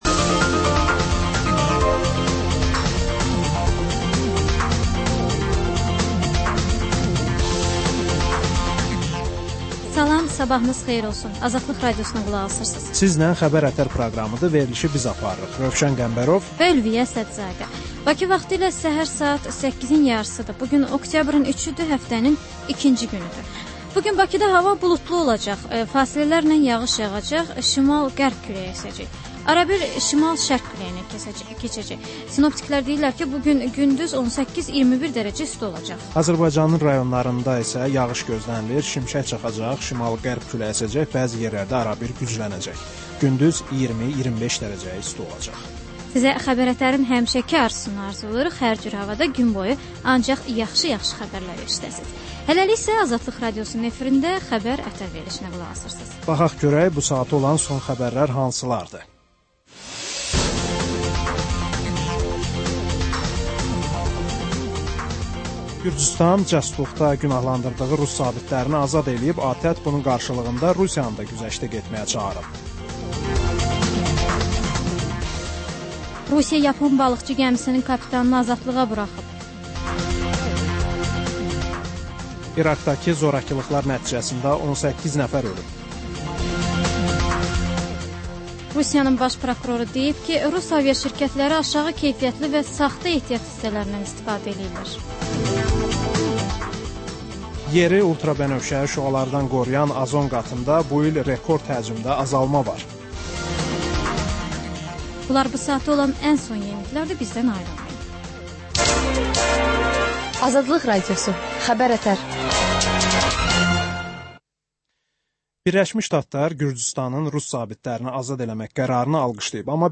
Səhər-səhər, Xəbər-ətərI Xəbər, reportaj, müsahibə